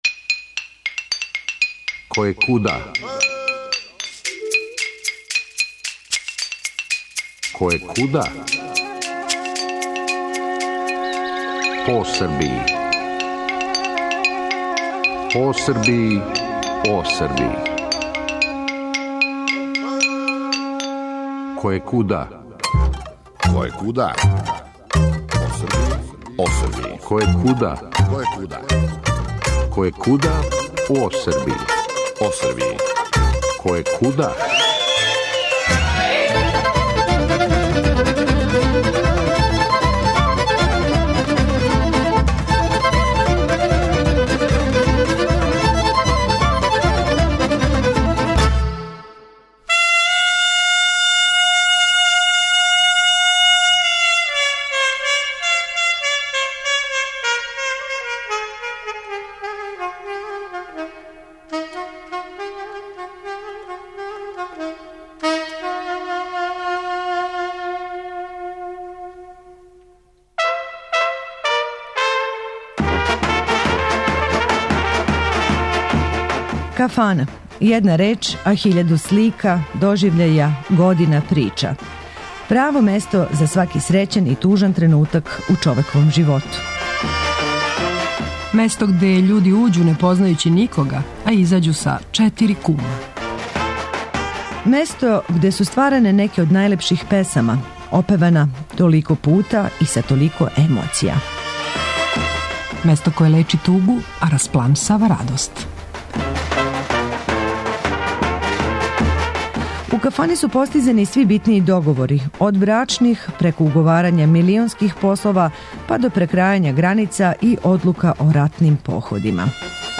Причао нам је професор о читавом једном начину живота који он зове "новокомпоновштина", где другде, него у једној, опет култној, кафани у Нишу, "Казанџијско сокаче".